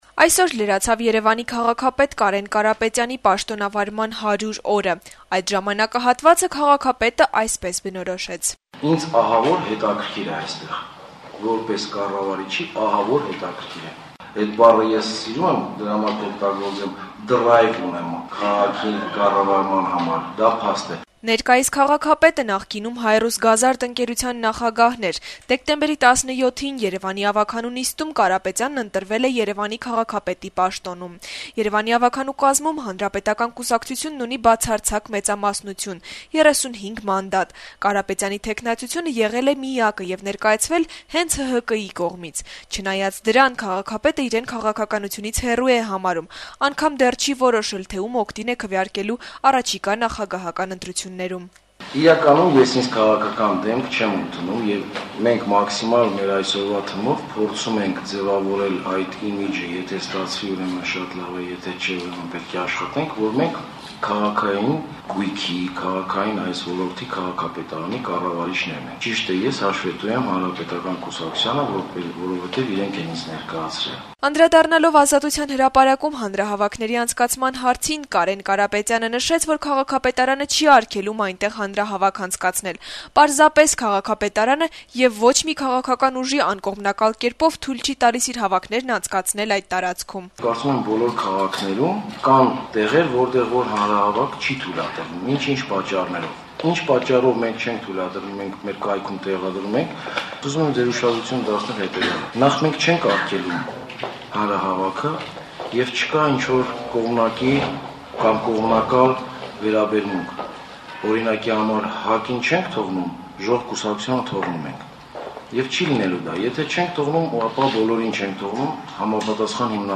«Ազատություն» ռադիոկայանի հետ զրույցներում երեւանցիները իրենց կարծիքները հայտնեցին նոր քաղաքապետի գործունեության վերաբերյալ: